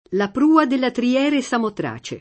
tri-$re] o triera [tri-$ra] s. f. (stor.); pl. ‑re (raro ‑ri) — grecismo per «trireme»: Né so dove guidi le ignote triere [ne SS0 ddove ggU&di le in’n’0te tri-$re] (Pascoli); La prua della trière samotrace [